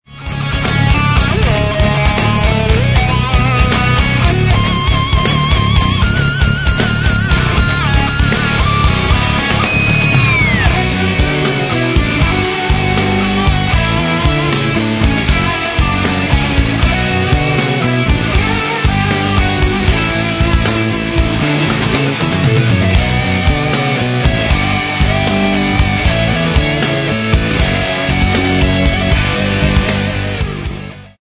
A Progressive Rock Site